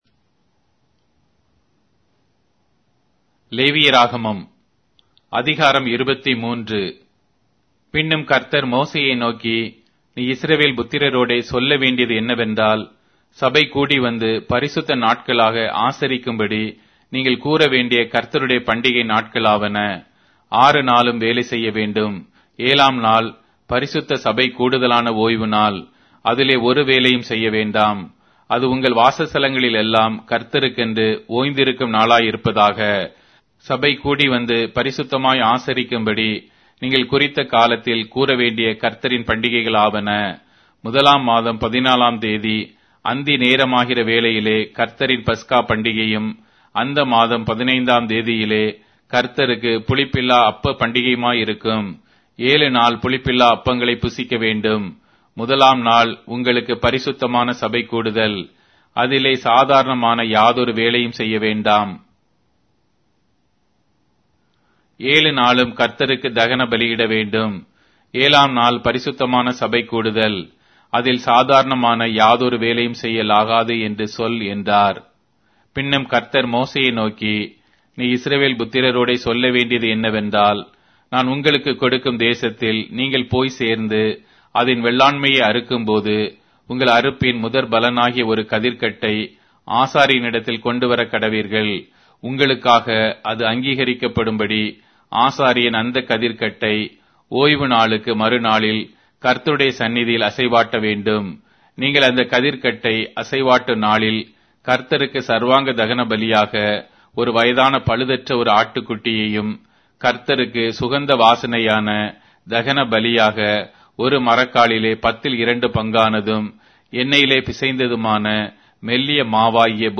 Tamil Audio Bible - Leviticus 27 in Ervpa bible version